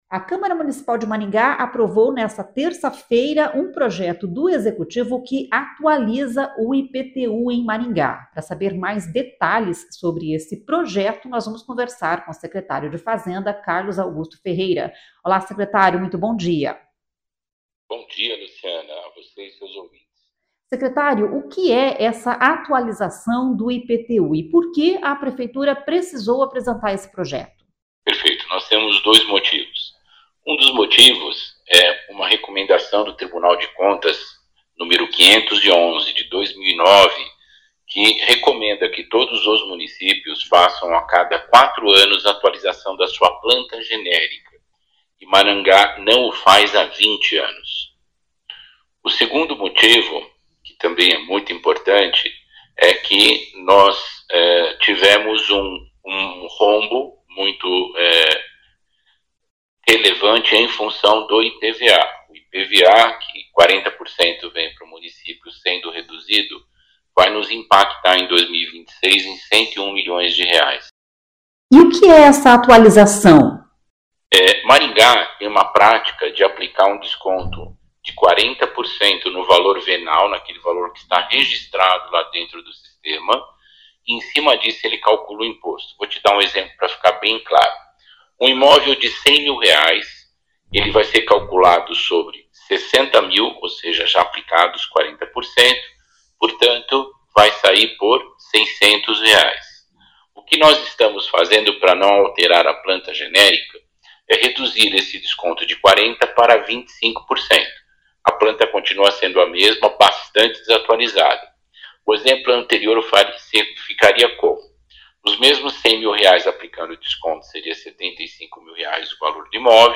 Entrevista
O secretário da Fazenda de Maringá, Carlos Augusto Ferreira, explicou em entrevista à CBN Maringá por que a Prefeitura decidiu reduzir o desconto no valor venal dos imóveis para cálculo do IPTU.